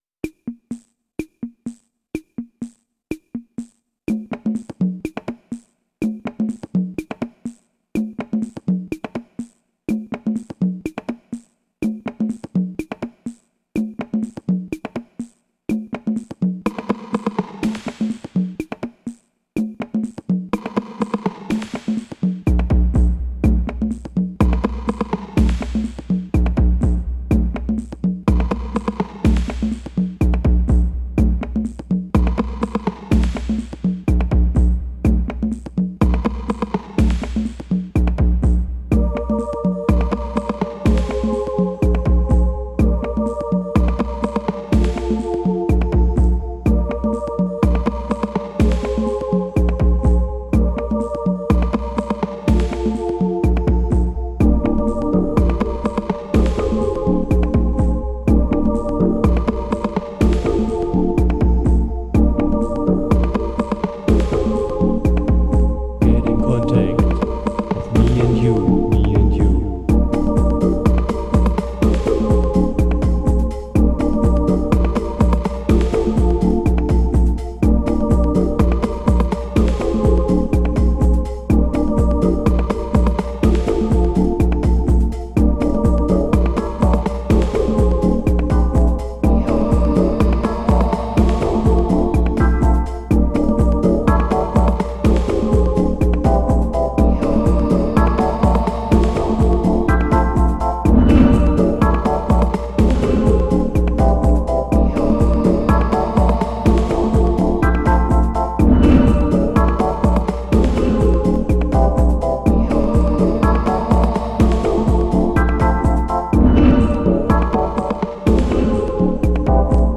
In den 90er Jahren hatte ich mir durch meine elektronischen Tüfteleien und dem Interesse an computergestützter Klangerzeugung die Möglichkeit zurechtgebaut, eigene Musikstücke zusammenzustellen.
Der Sound ist aus heutiger Sicht sicher total veraltet, die Stücke haben aber auch etwas antreibendes und dadurch energisches, modernes.
Der maschinenartige Sound der Übertragung aus der fernen Galaxie vom Stern Vega hat mich so mitgerissen, dass ich diesen unbedingt in meiner Musik verarbeiten musste.